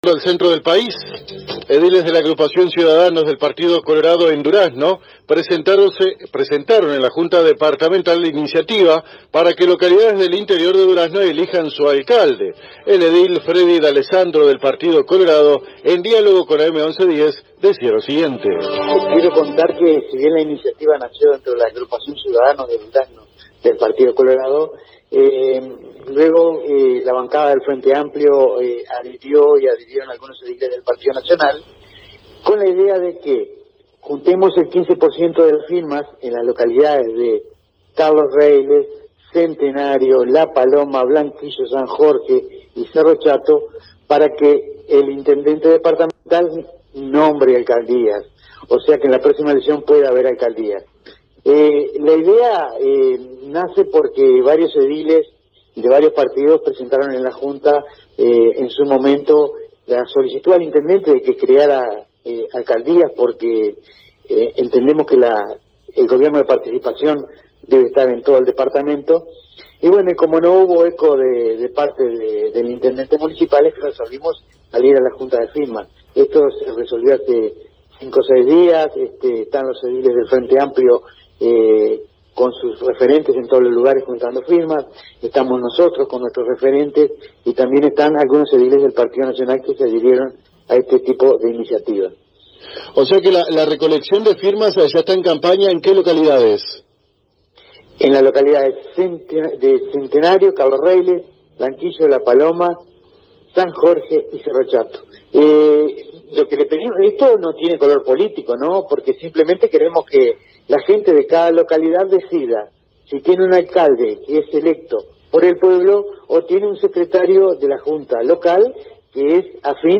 El edil Fredy D´Alessandro, en entrevista con la AM 1110 de Paso de los Toros, explicó que los referentes de los partidos políticos que conforman la Junta Departamental de Durazno, comenzarán esta semana un proceso de recolección de firmas en las localidades de Carlos Reyles, La Paloma, Blanquillo, San Jorge, Pueblo Centenario y Cerro Chato, a fin de que en las elecciones del 2024, sus votantes tengan la oportunidad de elegir un alcalde.
Escucha a Fredy D´Alessandro aquí: